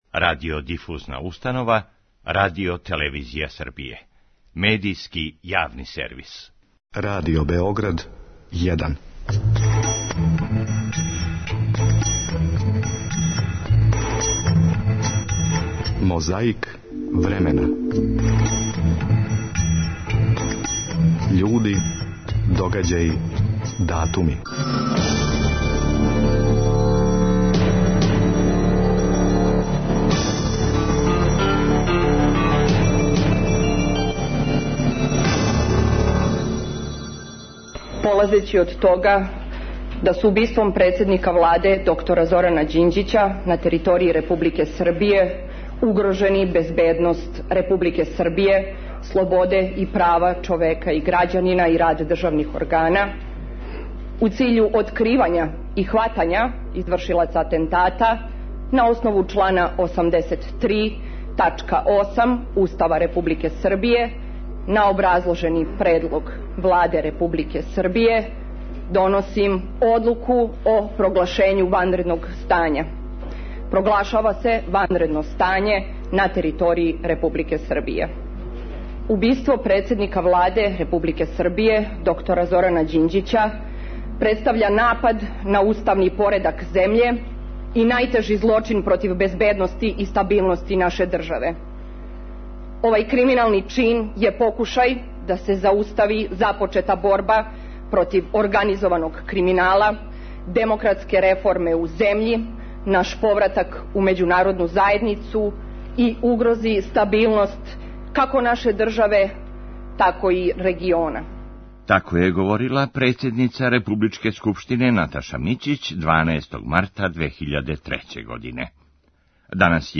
На конференцији за новинаре одржаној 15. марта 2006. године у Медија центру у Београду, о последњим данима Слободана Милошевића у Схевенингену, говорио је Момир Булатовић.
Подсећа на прошлост (културну, историјску, политичку, спортску и сваку другу) уз помоћ материјала из Тонског архива, Документације и библиотеке Радио Београда.